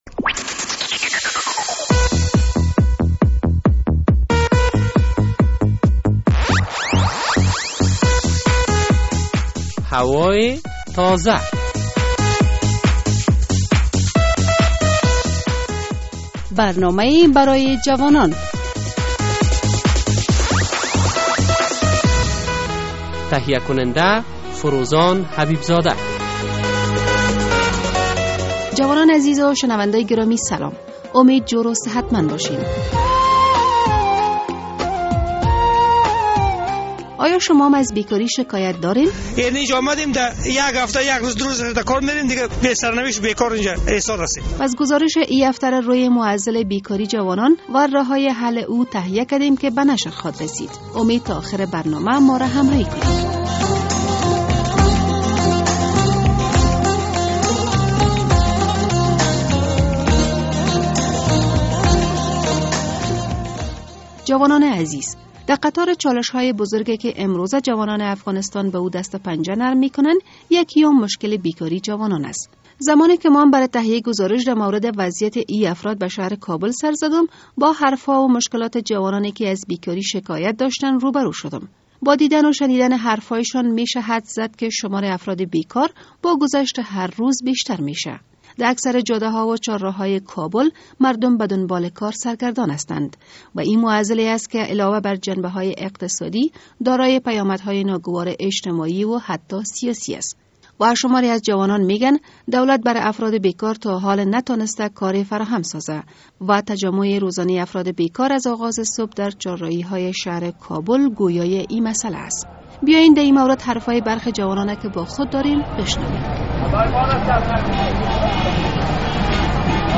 در قطار مشکلات بزرگ که امروزه جوانان افغانستان به آن دست و پنجه نرم می کنند، یکی هم مشکل بیکاری آنهاست. زمانیکه برای تهیه گزارش در مورد وضعیت این افراد به شهر کابل سرزدم با حرف ها و مشکلات جوانان که از بیکاری شکایت داشتند، روبرو شدم...